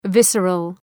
Προφορά
{‘vısərəl}